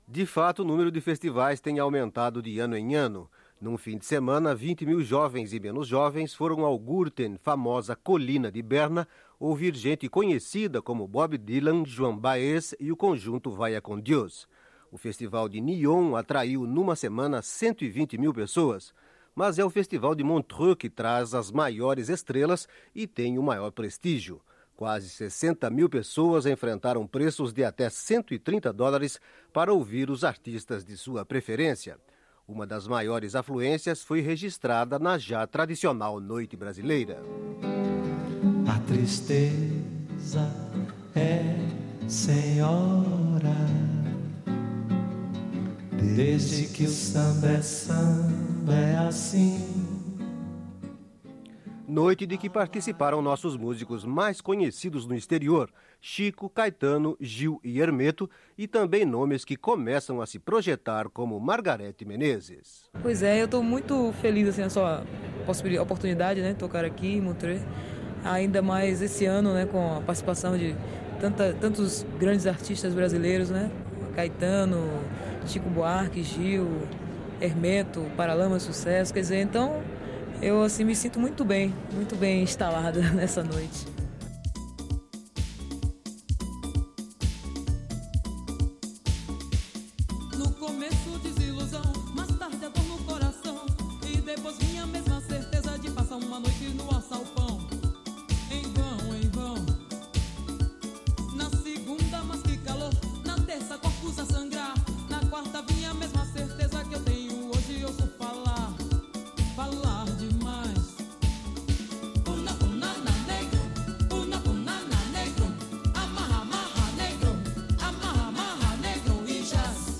A reportagem que você vai ouvir a seguir é a do festival de 1993.